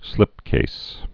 (slĭpkās)